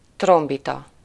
Ääntäminen
Ääntäminen France: IPA: [tʁɔ̃.pɛt] Lyhenteet ja supistumat (musiikki) Tr.